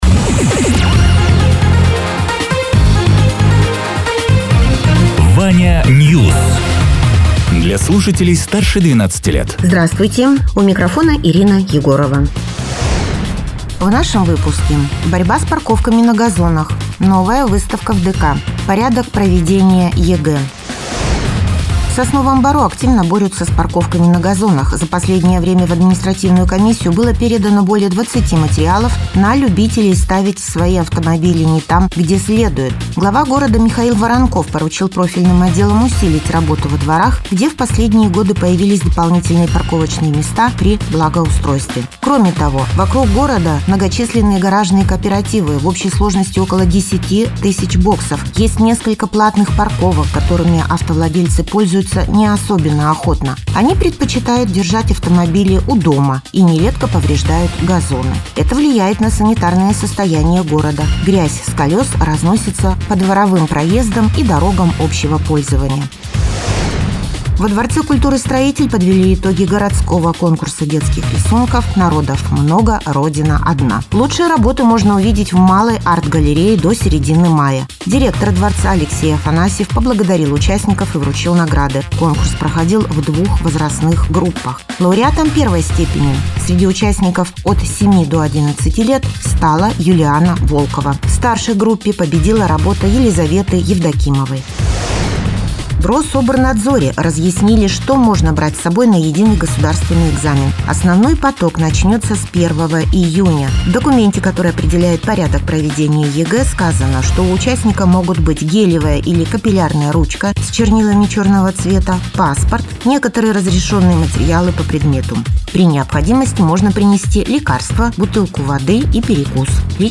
Радио ТЕРА 23.04.2026_10.00_Новости_Соснового_Бора